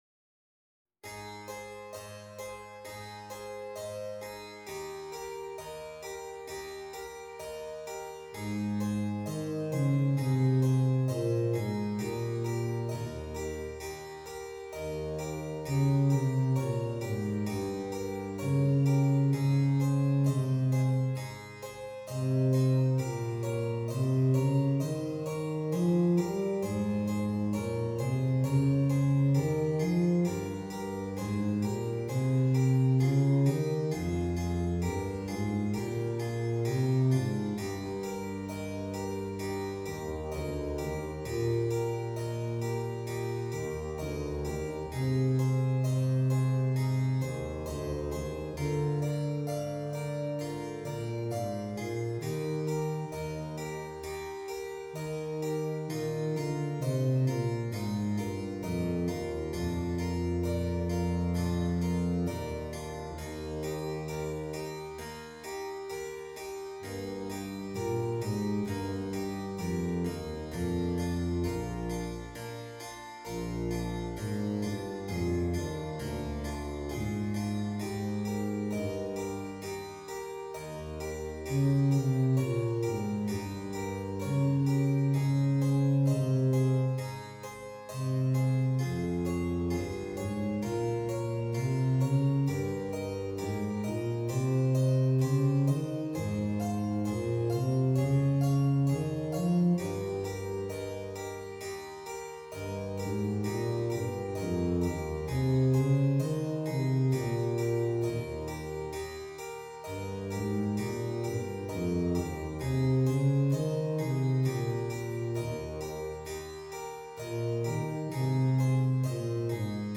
Tuba and Keyboard